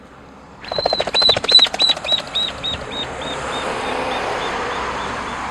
Nothoprocta ornata
Nome em Inglês: Ornate Tinamou
Condição: Selvagem
Certeza: Gravado Vocal